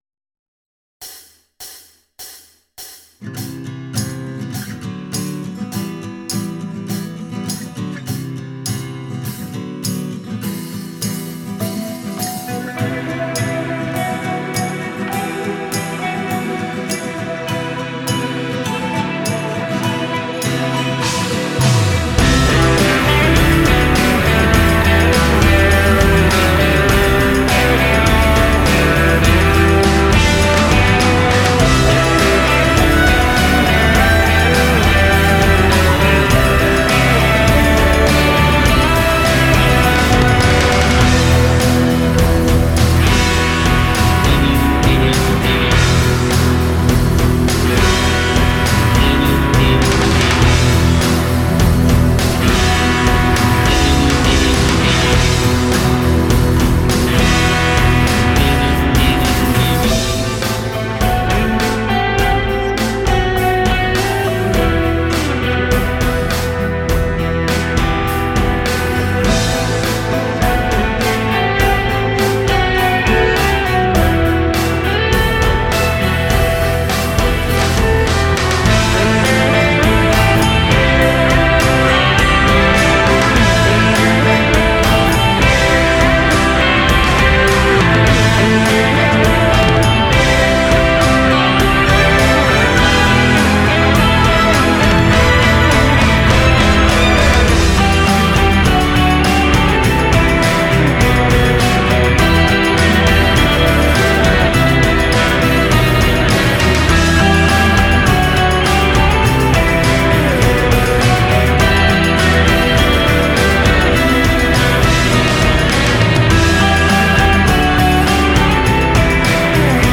played in its majority with a guitar